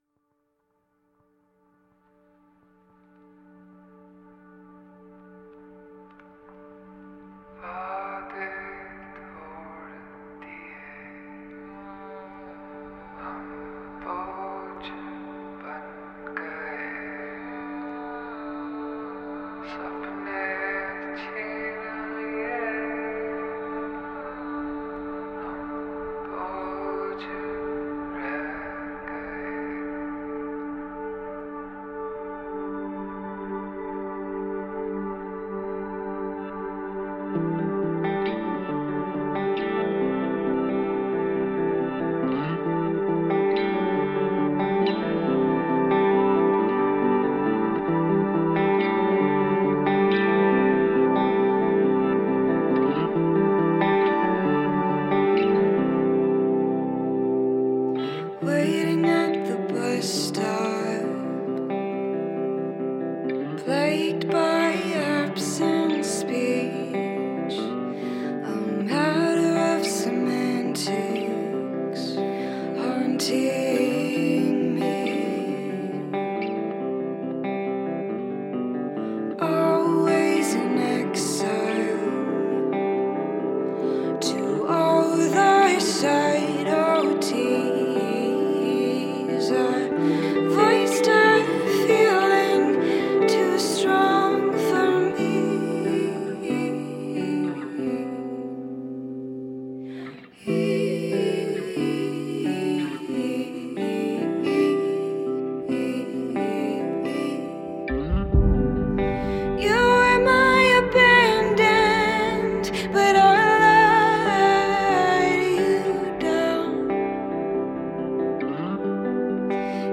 dream-folk